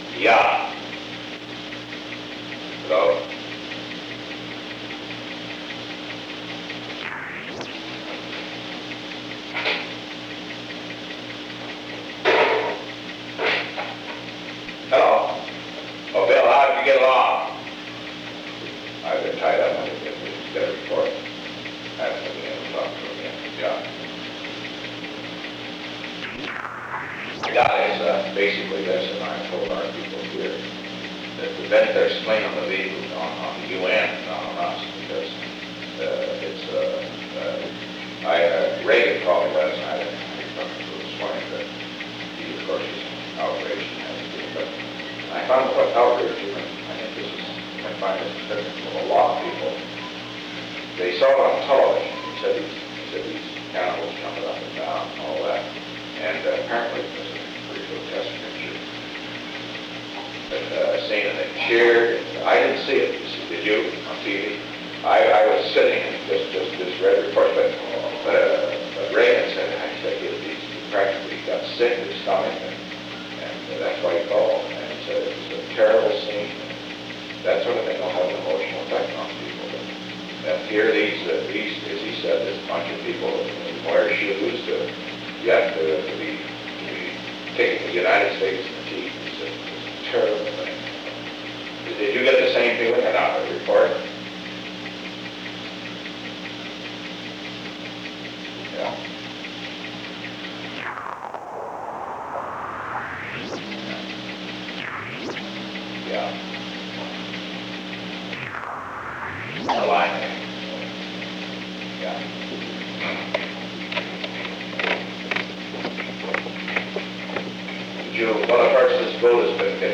The Old Executive Office Building taping system captured this recording, which is known as Conversation 303-002 of the White House Tapes.
The President talked with William P. Rogers.
The President hung up due to poor telephone connection.